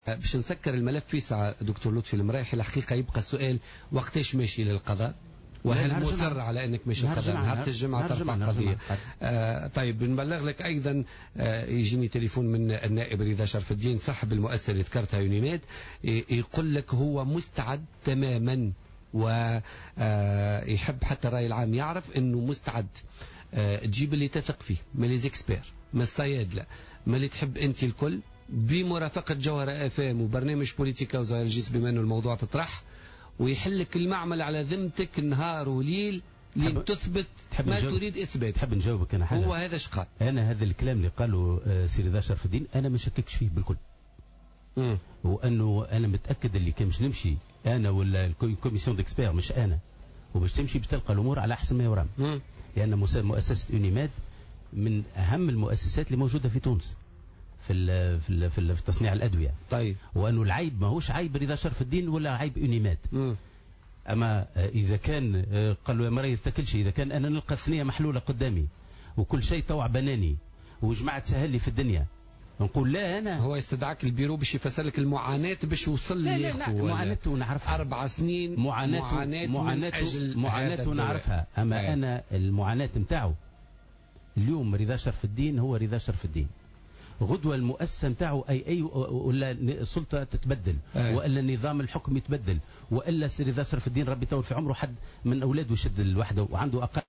قال النائب بمجلس نواب الشعب عن حزب نداء تونس رضا شرف الدين في تصريح للجوهرة أف أم اليوم الأربعاء 10 فيفري 2016 في برنامج بوليتكا إنه مستعد لفتح مؤسسته للتحقيق من قبل أي لجنة خبراء أو مختصين تريد ذلك.